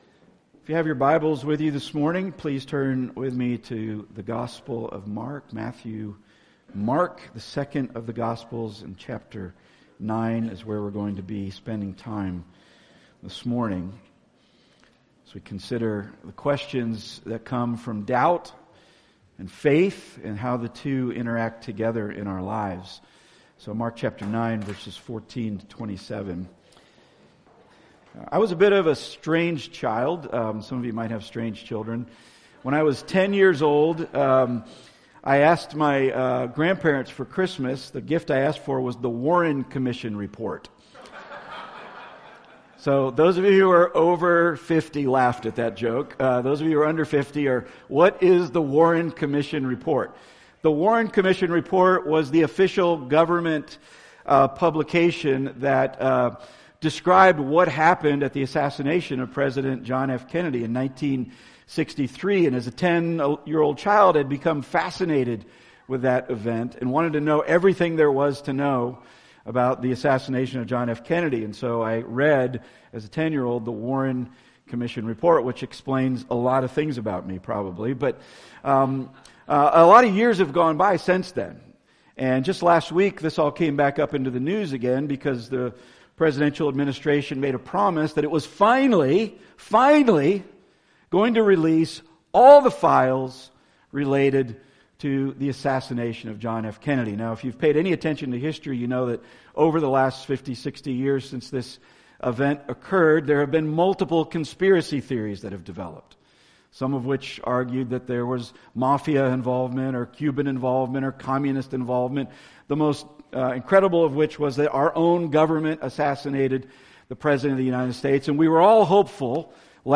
John 20:24-31 Service Type: Weekly Sunday